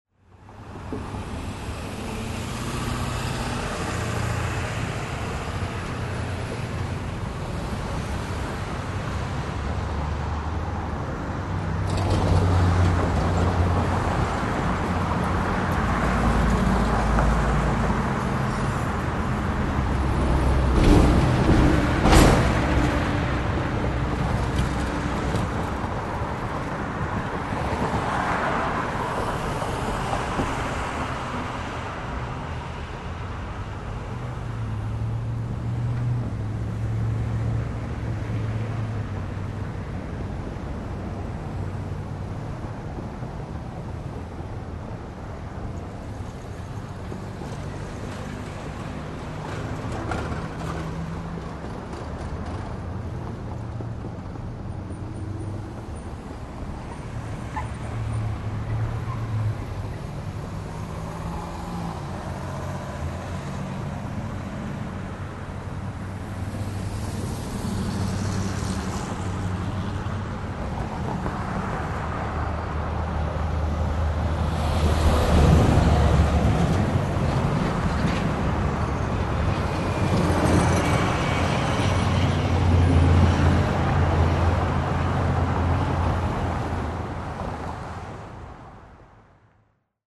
Звуки городской улицы
На этой странице собраны разнообразные звуки городской улицы: гул машин, голоса прохожих, сигналы светофоров и другие фоновые шумы мегаполиса.
Шум городской улицы и звуки автомобилей